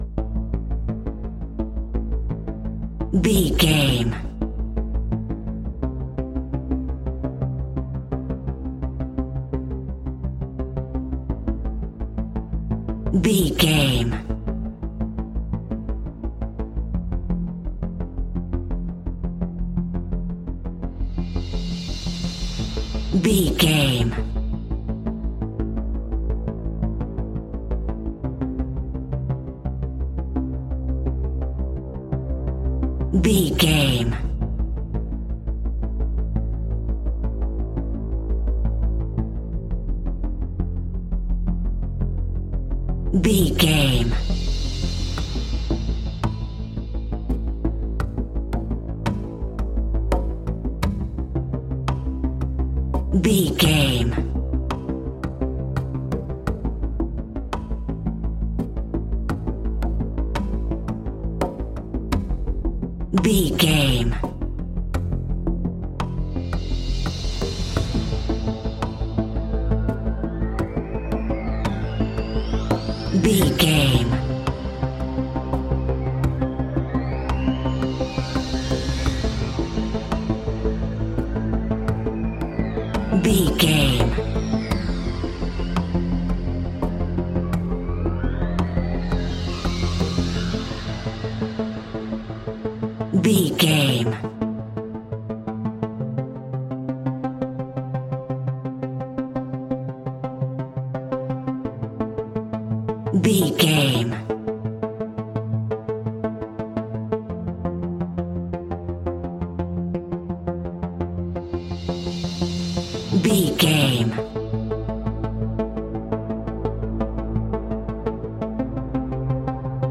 In-crescendo
Thriller
Aeolian/Minor
ominous
dark
haunting
eerie
strings
synthesiser
drums
instrumentals
horror music